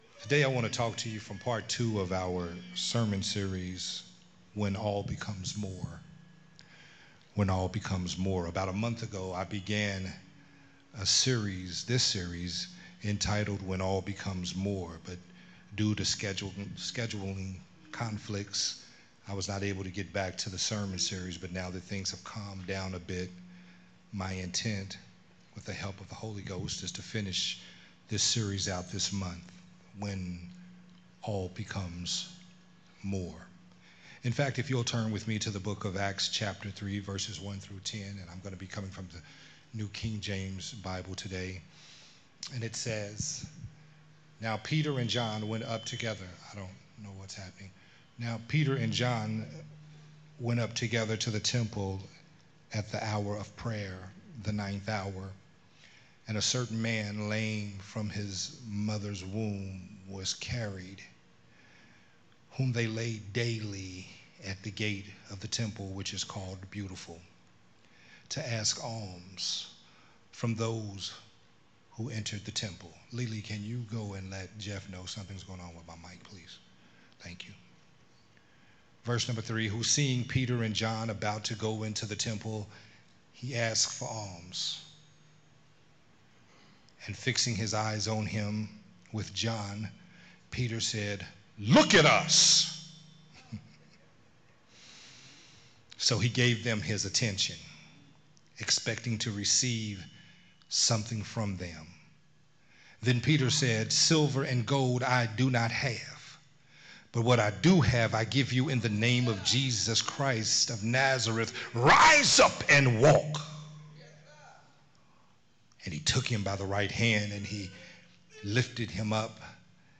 Part 2 of the sermon series
recorded at Unity Worship Center on Sunday, September 15th, 2024.